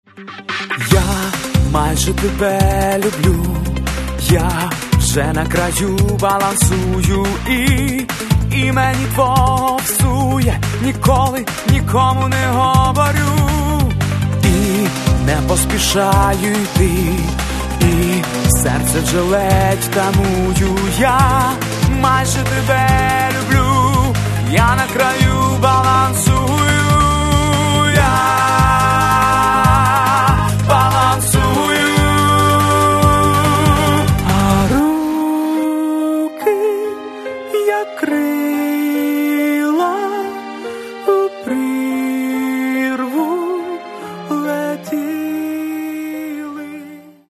Каталог -> Поп (Легкая) -> Лирическая